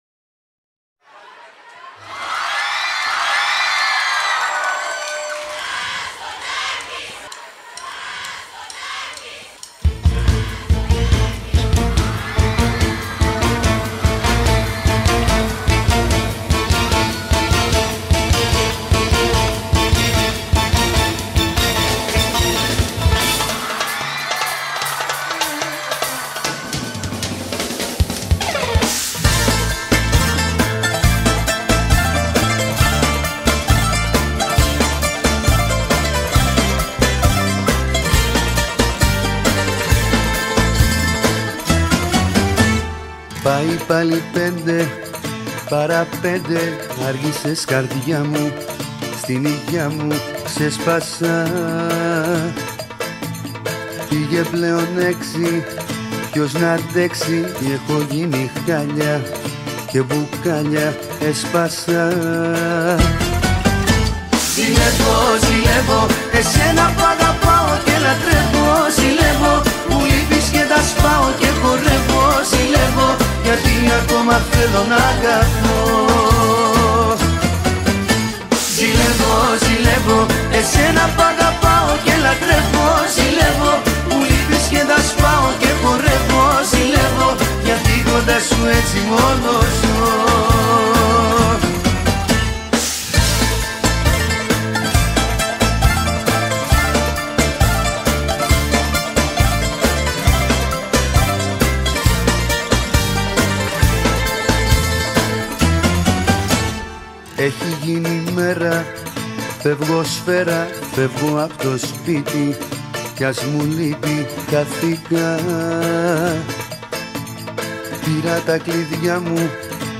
اول این اهنگ یک صدای جیغی هم همراه با فریاد فامیلیش می‌شنویم